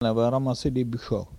Patois - archives